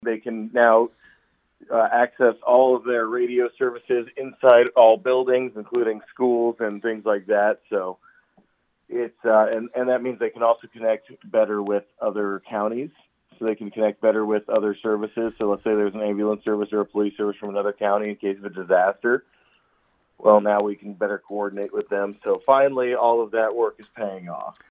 Audubon County Supervisor Chairman Heath Hanson says all the testing is complete, and the tower is fully operational.